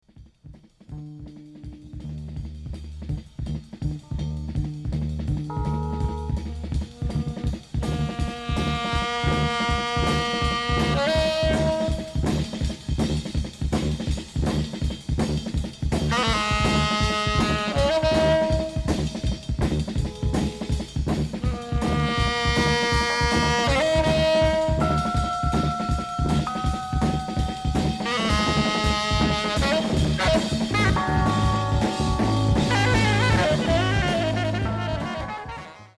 Tenor and soprano saxophones
Electric piano and moog synthesizer
Electric and acoustic basses
Drums, congas, bongos and bell